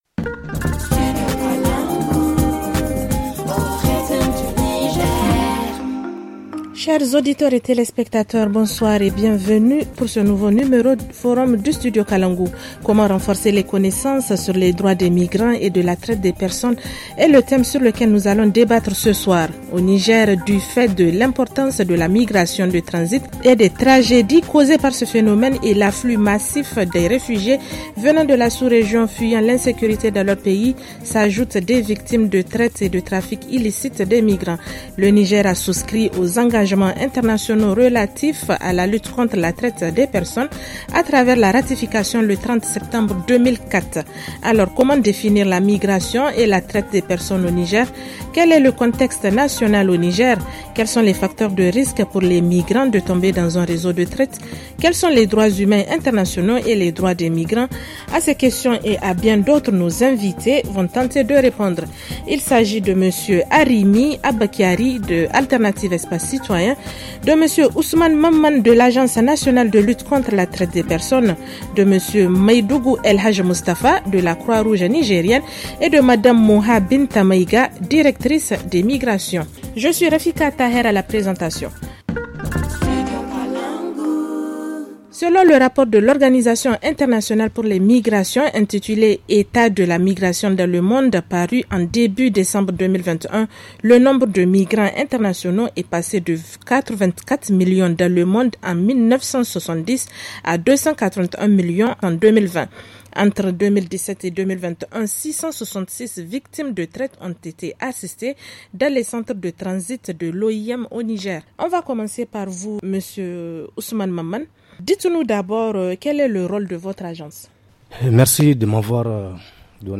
[Rediffusion] Comment renforcer les connaissances sur les droits des migrants et de la traite des personnes ?